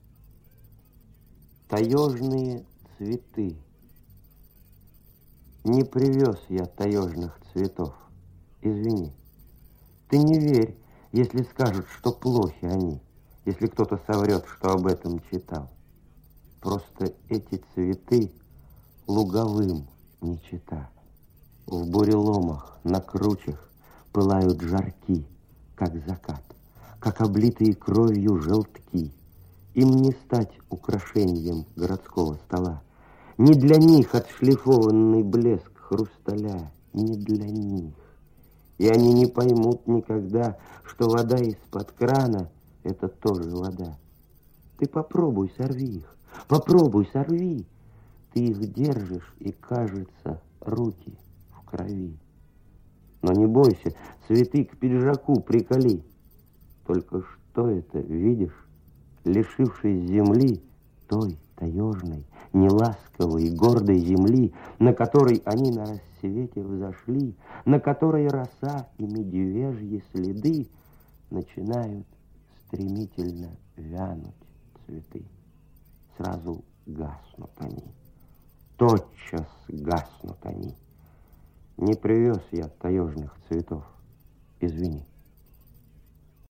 2. «Чит. Николай Караченцов – Р.Рождественский – Таежные цветы» /